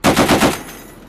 .50 cal bursts
Isolated from a test firing range video.
Burst lengths vary a little, it will give your unit a little variety so as not to sound repetitive.